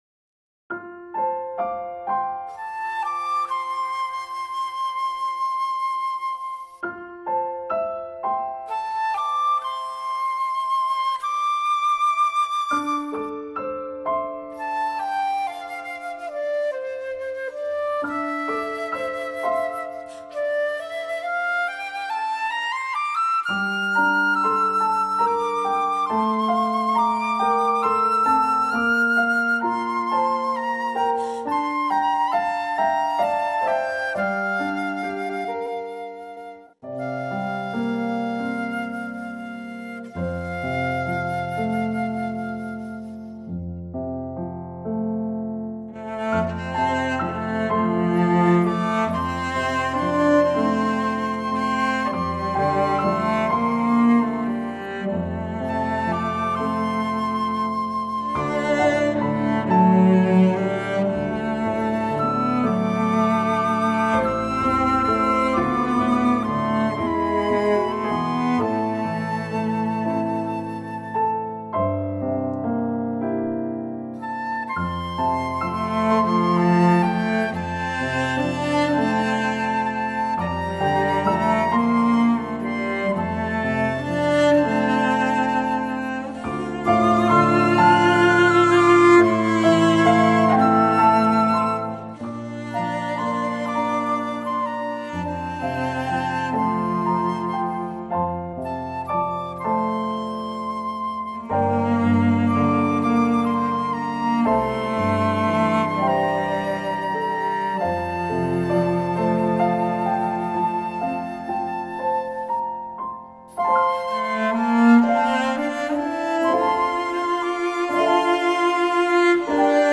Three Part Men, SA Flute, Violin